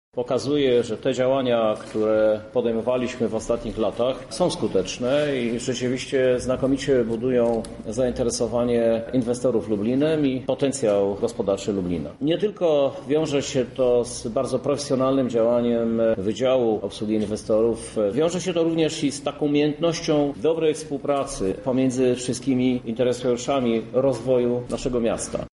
Ten raport jest dla nas bardzo ważny – mówi Prezydent Lublina, Krzysztof Żuk: